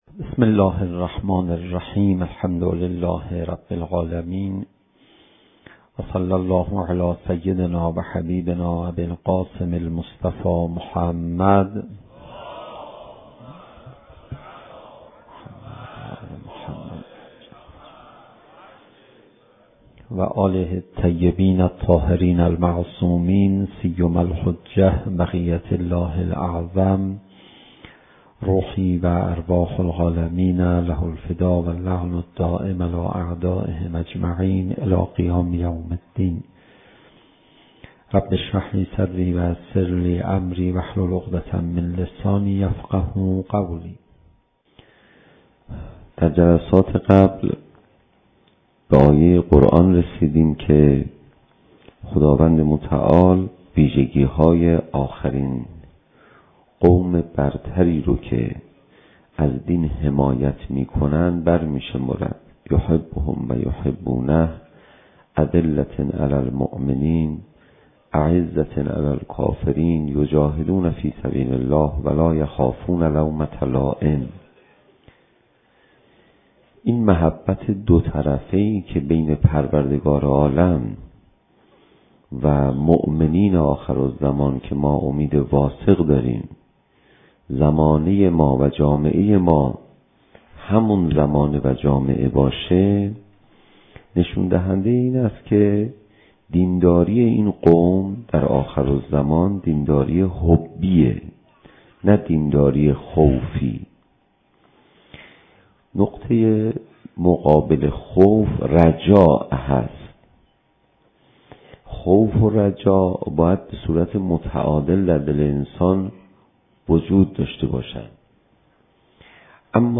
زمان: 35:11 | حجم: 8.35 MB | تاریخ: 1393 | مکان: حسینیة آیت الله حق شناس